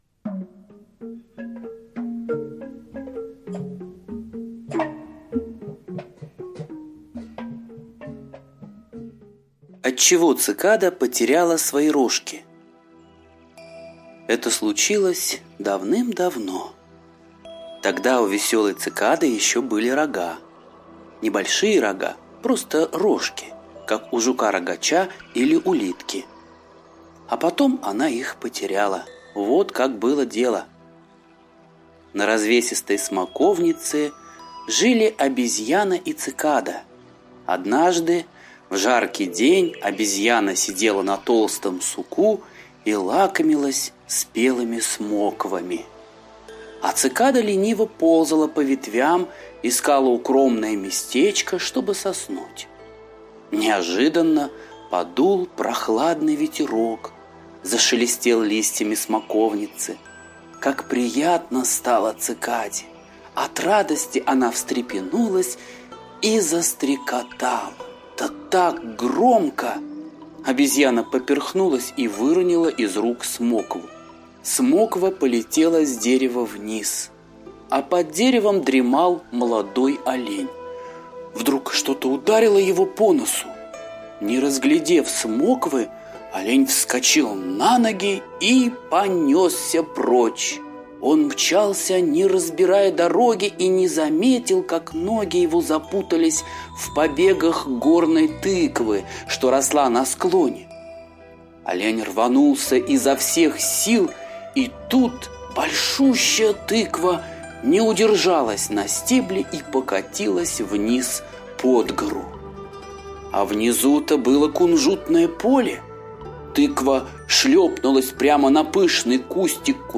Отчего Цикада потеряла свои рожки - восточная аудиосказка - слушать онлайн